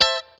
CHORD 1   AH.wav